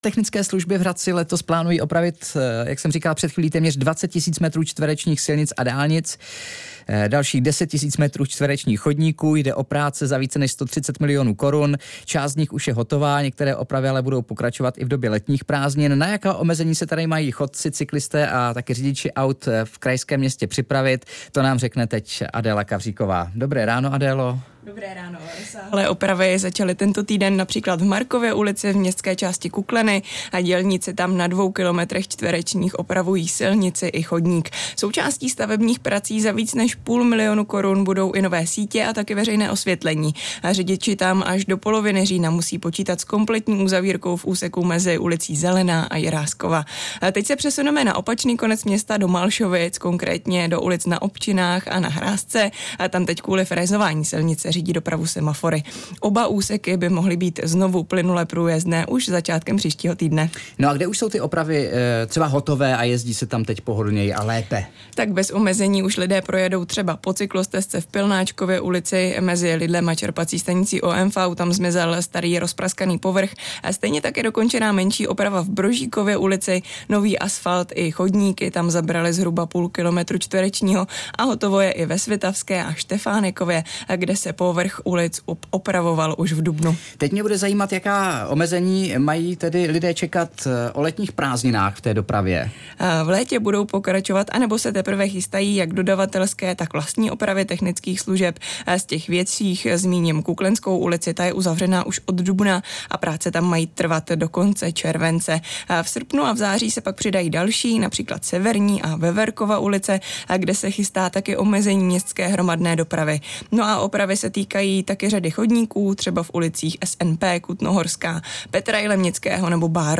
Host ve studiu: Hradecké minuty! Módní svět - klobouky - 22.06.2025